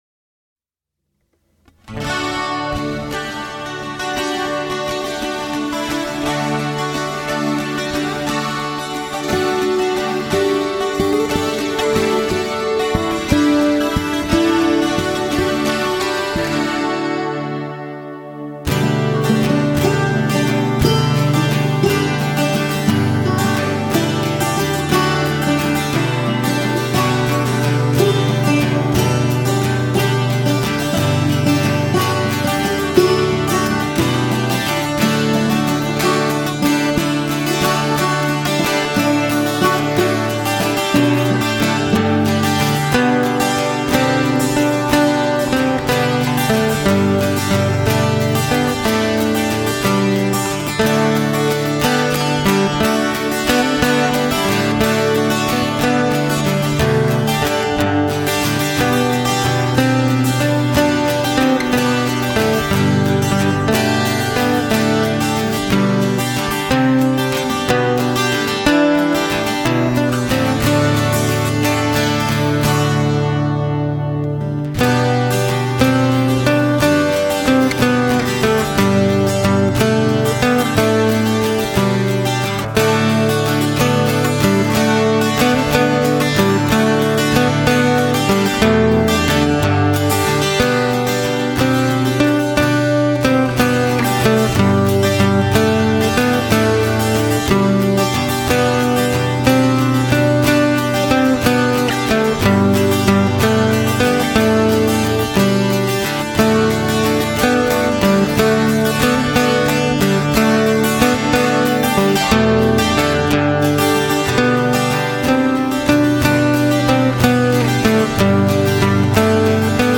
We were watching a movie and this song came up and I realized I did a guitar cover of it.